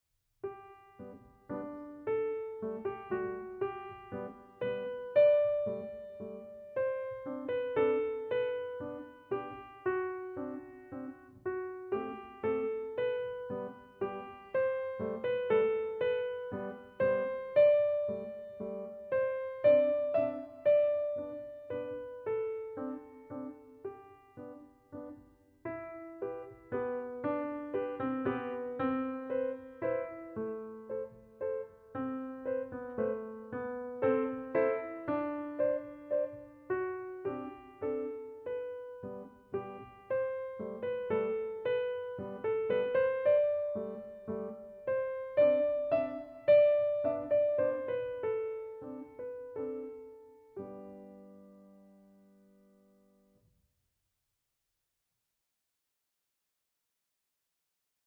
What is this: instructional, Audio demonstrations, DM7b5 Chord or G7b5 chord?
Audio demonstrations